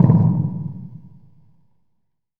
TC3Perc1.wav